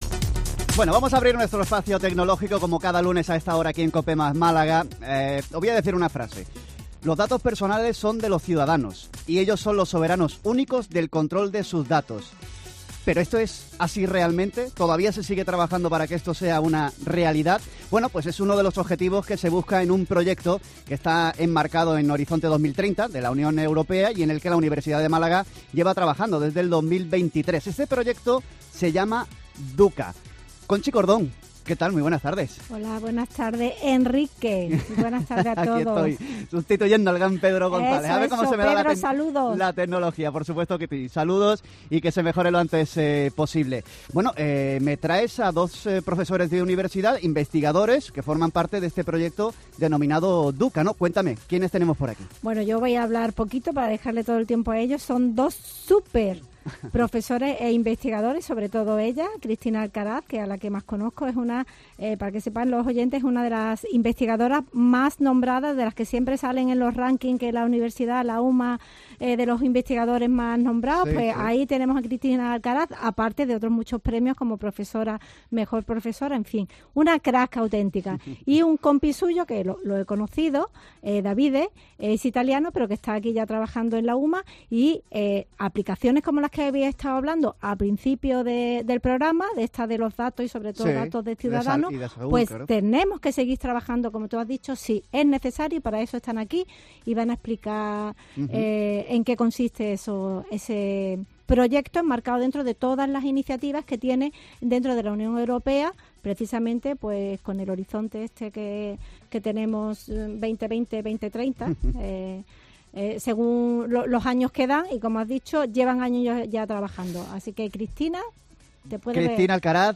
• NICS Lab has participated in the National Interview “COPE Malaga” the last February 24th, 2025.